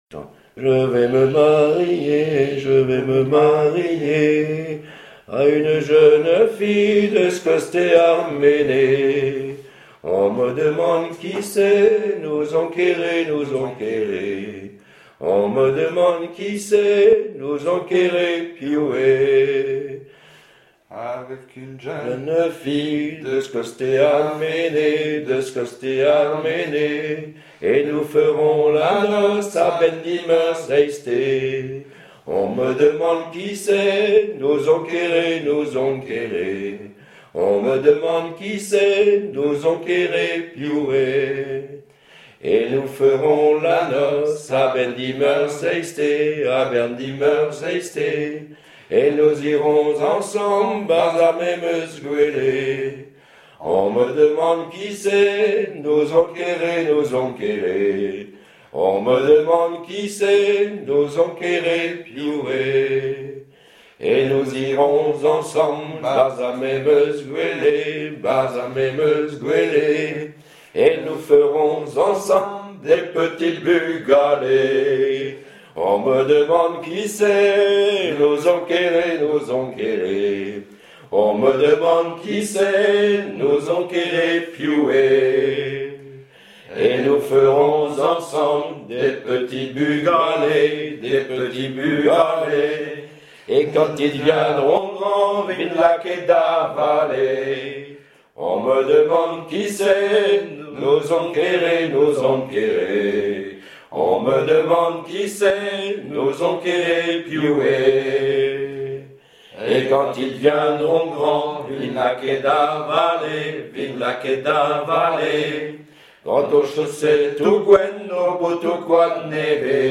Genre laisse
Chansons et témoignages
Pièce musicale inédite